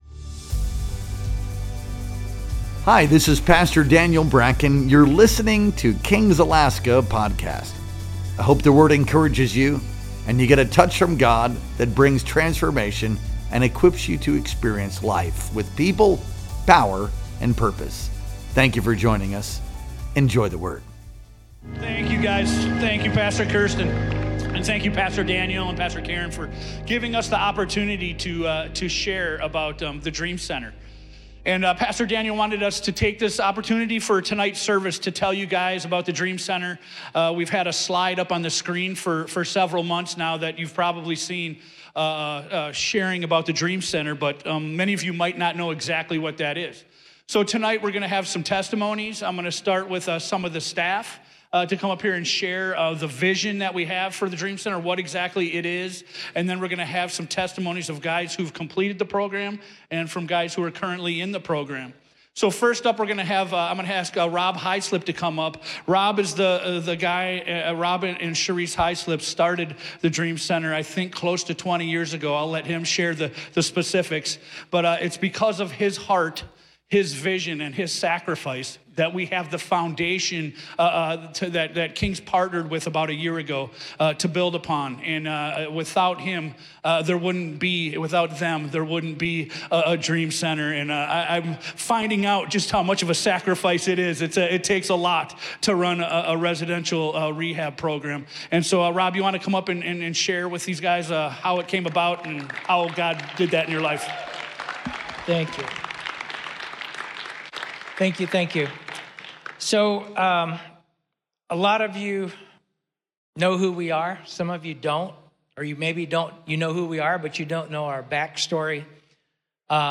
Our Sunday Night Worship Experience streamed live on May 25th, 2025.